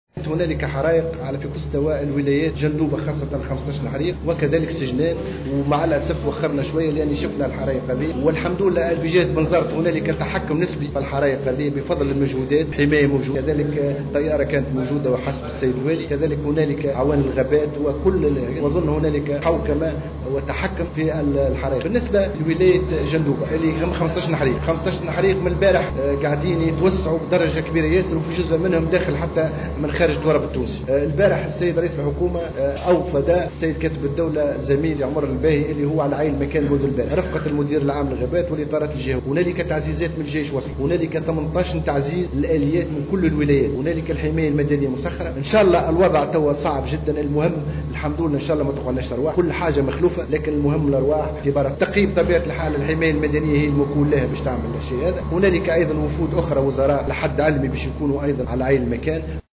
أكد عبد الله الرابحي كاتب الدولة للمياه أثناء تفقده عملية اخماد الحريق الذي اندلع فجر اليوم بمنطقة الزوبية من معتمدية سجنان أن كل أجهزة الدولة مستنفرة لإخماد سلسلة الحرائق التي اندلعت بالتزامن في عدة مناطق مثل بنزرت وجندوبة والكاف.